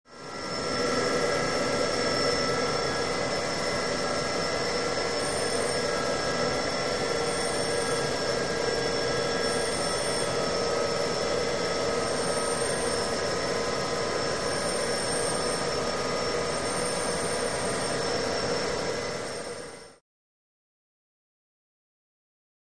Machine With Whines And Whistles With Steady Drone